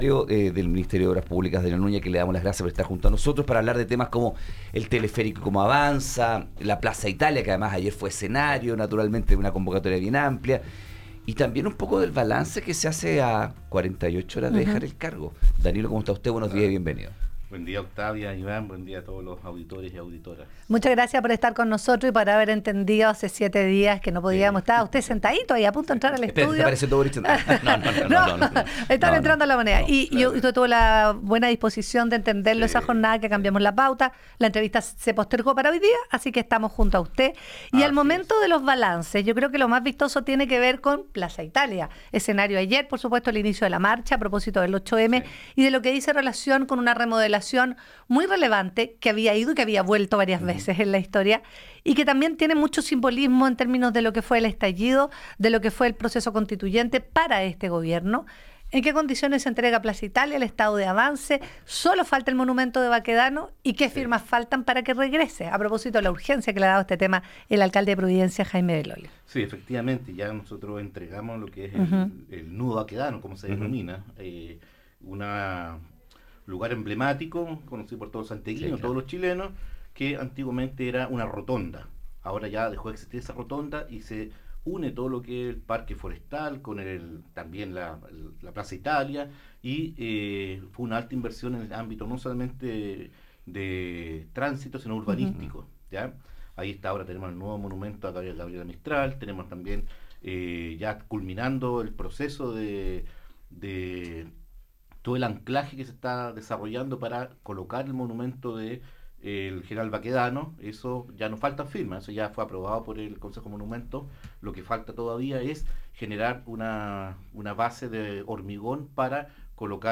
UNIVERSO – Hoy en Universo al Día conversamos con el subsecretario de Obras Públicas, Danilo Núñez, quien abordó el estado de avance de distintas obras urbanas en Santiago, entre ellas la remodelación del eje de Plaza Baquedano y el proyecto del Teleférico Bicentenario.